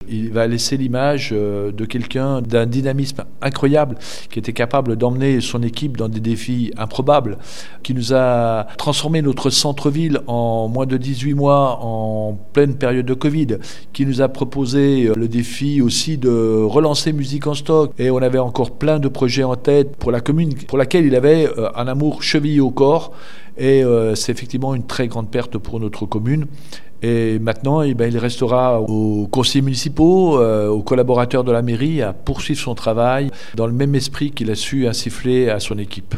Suite à un début de mandat particulier en raison de la crise sanitaire, il s’était révélé par l’ambition qu’il portait pour sa commune, notamment à travers deux grands dossiers. Gérald Richard, premier adjoint, nous en parle.